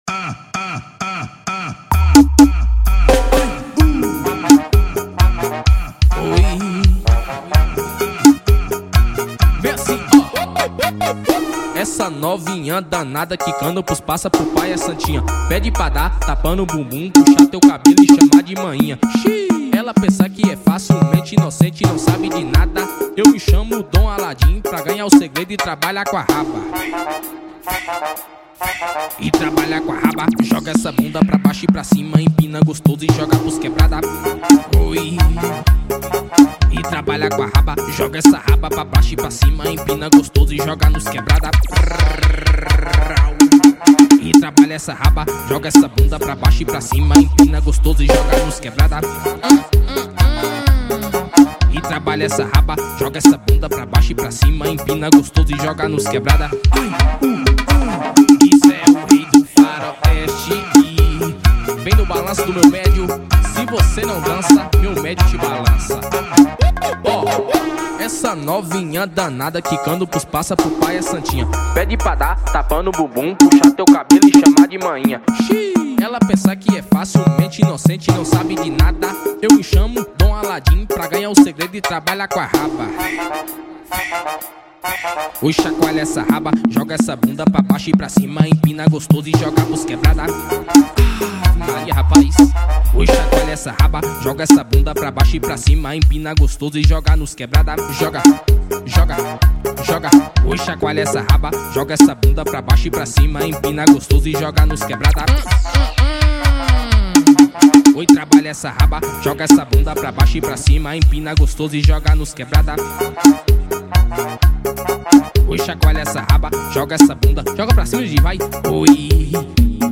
EstiloArrochadeira